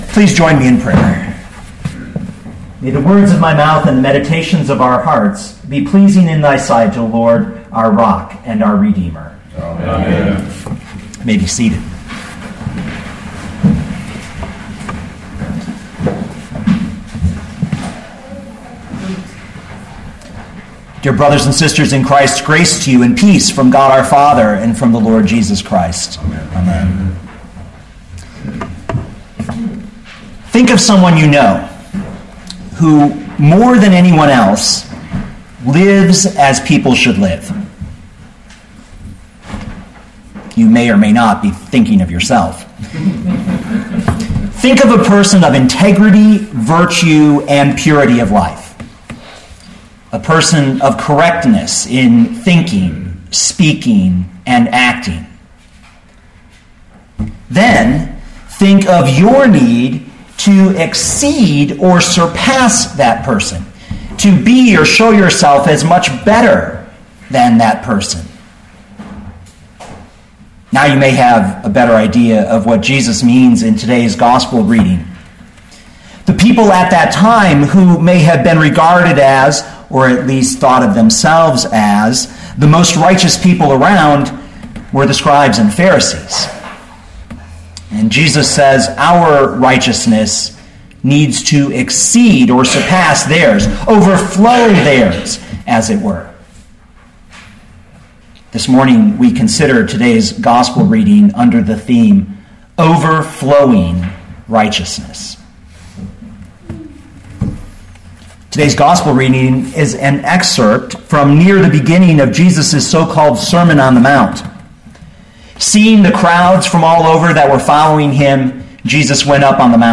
2014 Matthew 5:13-20 Listen to the sermon with the player below